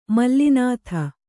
♪ malli nātha